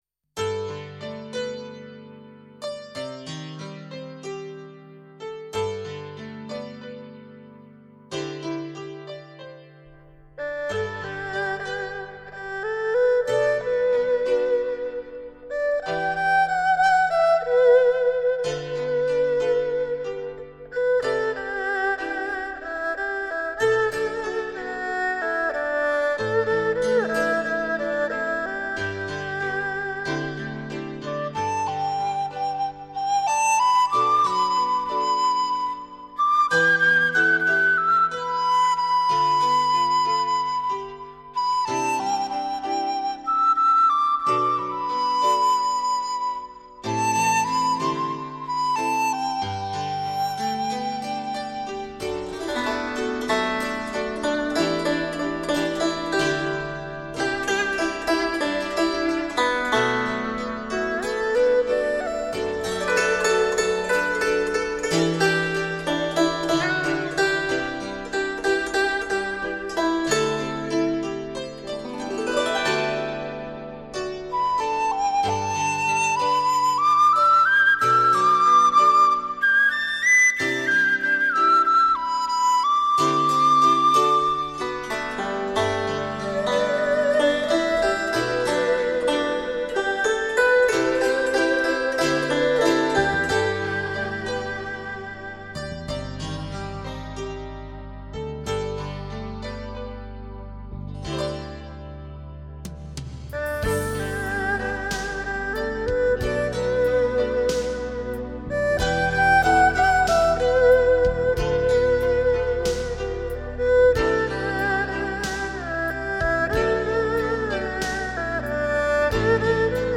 此系列编曲方面比较柔和一些，适合闲情时候欣赏的民乐器轻音乐。